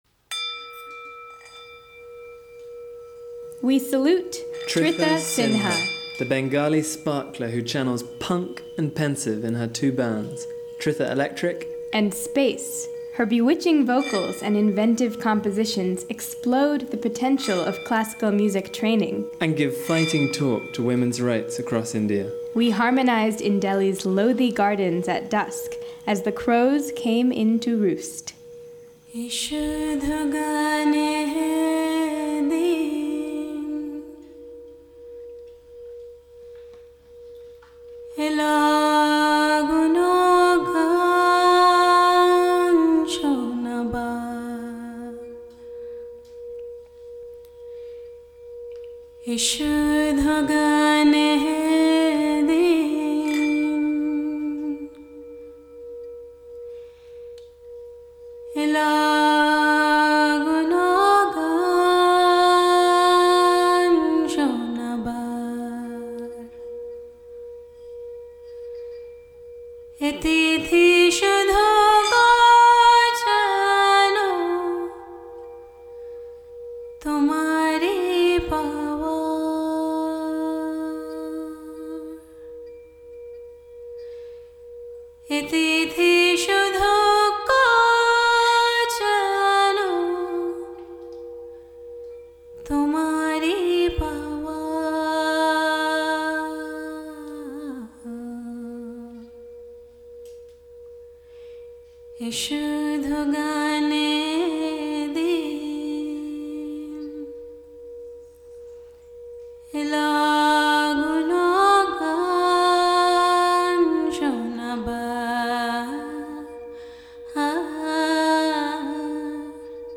The songstress embracing insanity amid birdsong and fishmongers.